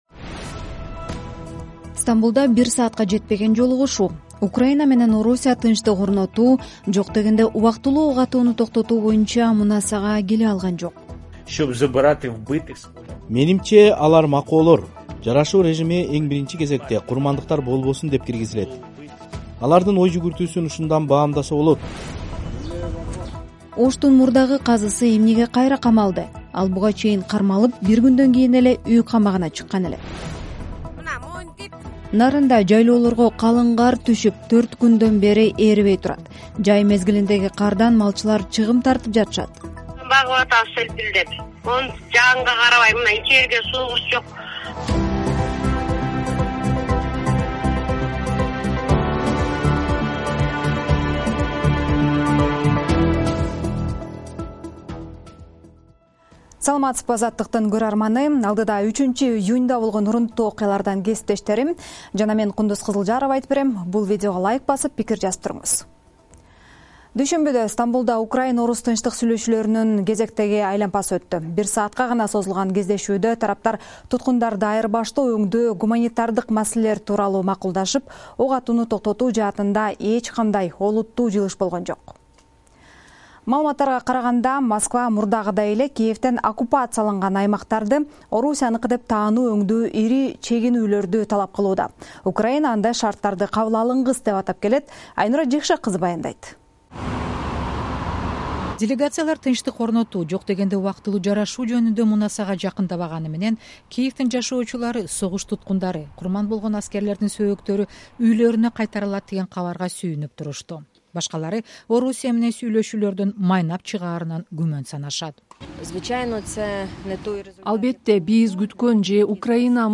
Жаңылыктар | 03.06.2025 | Оштун мурдагы казысы экинчи жолу камалды